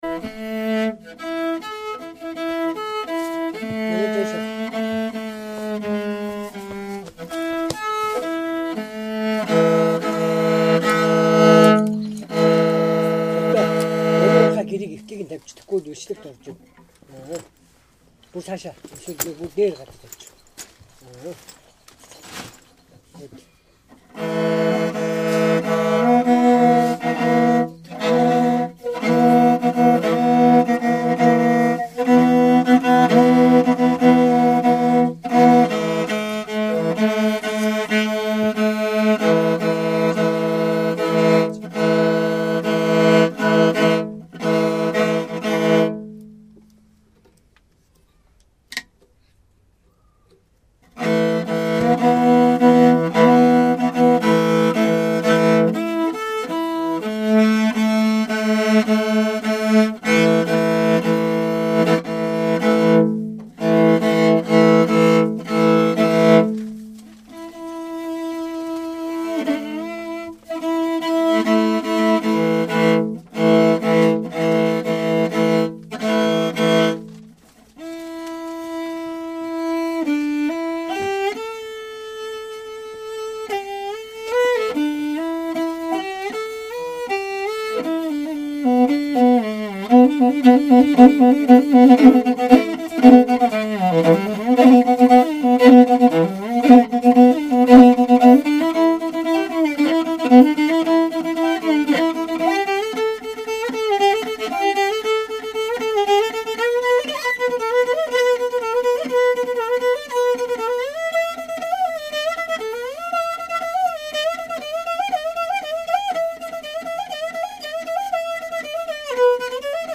morin khuur